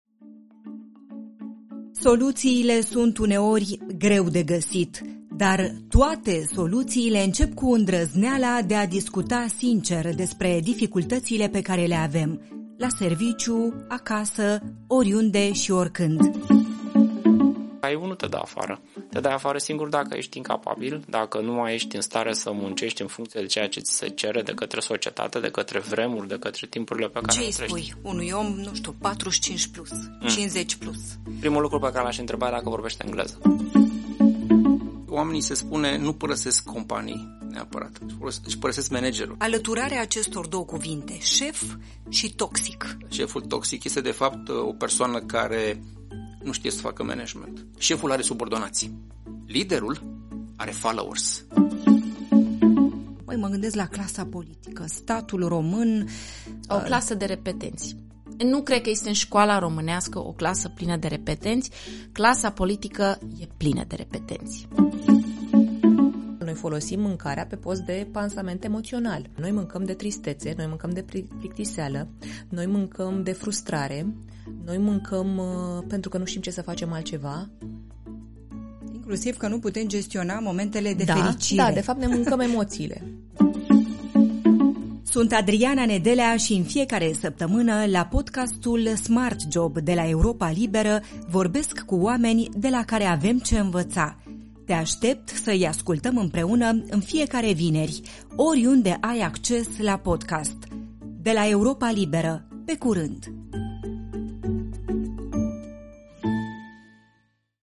Doar așa putem găsi soluții, discutând sincer despre dificultățile pe care le avem. Conversații cu oameni de la care avem ce învăța.